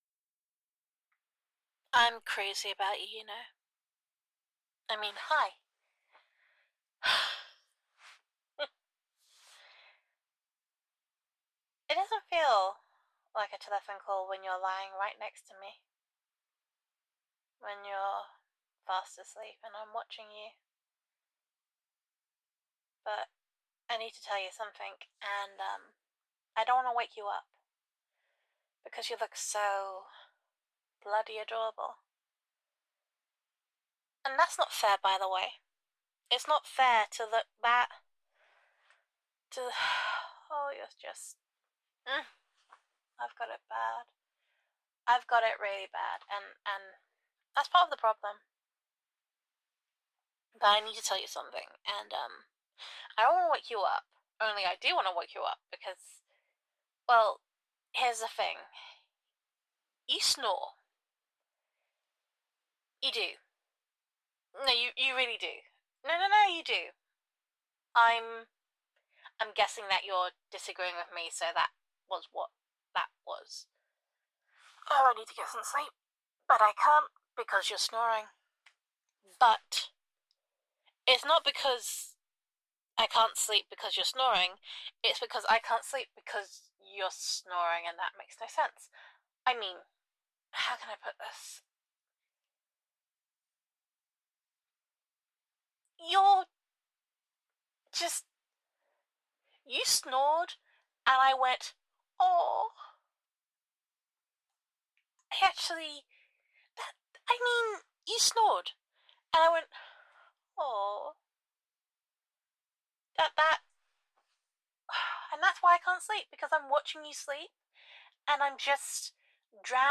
[F4A] Crazy About You [Unfairly Adorable][You Snore][Love Addled Mess][Love Confession][Telephone EQ][Gender Neutral][Girlfriend Voicemail]